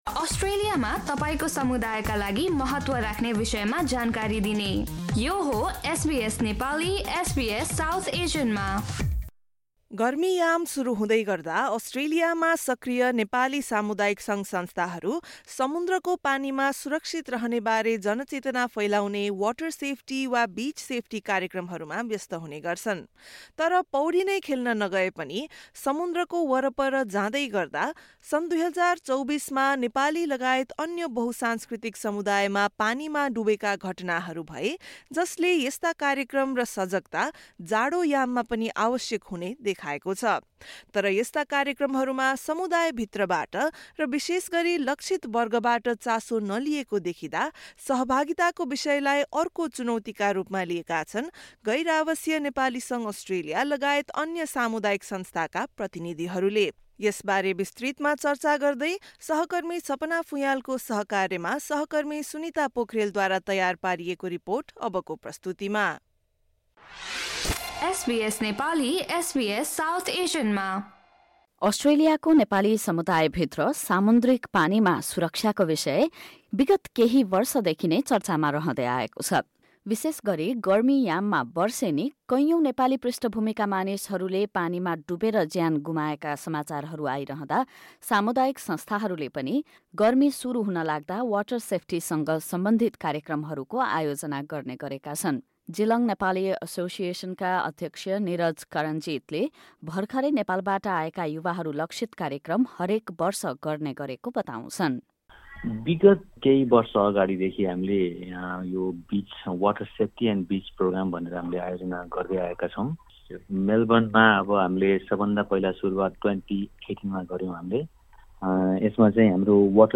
अस्ट्रेलियामा भर्खरै आएका विशेष गरी नेपाली युवाहरूलाई सामुन्द्रिक पानीमा सुरक्षित रहने बारे सैद्धान्तिक मात्र नभएर व्यवहारिक ज्ञान दिने तर्फ ध्यान दिइनु पर्ने समुदायका अगुवाहरूले बताएका छन्। 'वाटर सेफ्टी' वा 'बीच सेफ्टी' बारे समुदायको सचेतनालाई लिएर अस्ट्रेलियाका विभिन्न राज्य तथा प्रदेशका सामुदायिक संस्थामा आबद्ध व्यक्तिहरूले एसबीएस नेपालीसँग गरेको कुराकानी सुन्नुहोस्।